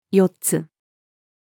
四つ-female.mp3